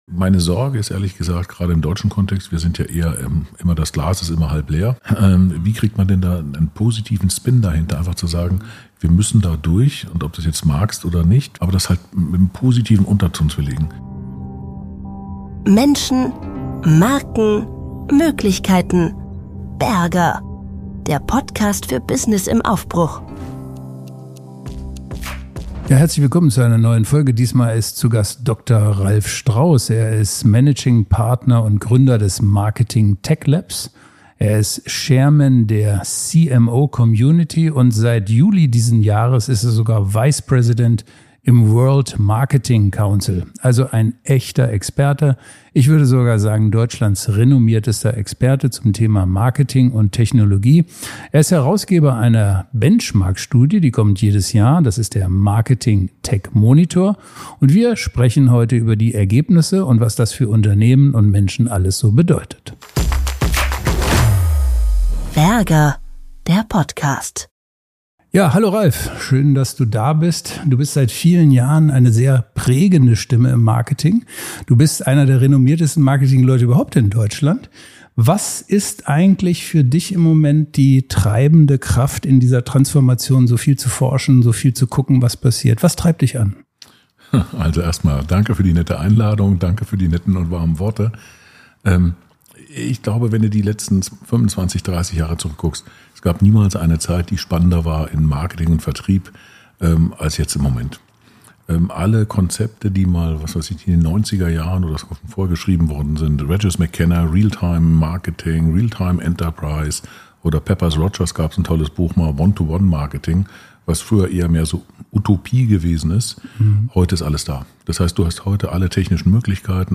Wir reden über die Ergebnisse des aktuellen Marketing Tech Monitors: Von Over-Tooling und der Gefahr, in Tool-Wildwuchs zu versinken, über die Notwendigkeit neuer Kompetenzen in Marketing-Teams, bis hin zur Frage, wie KI-Agenten in Zukunft Prozesse verändern werden. Ein Gespräch über Organisation, Kultur und Haltung – und darüber, warum am Ende doch der Mensch den Unterschied macht.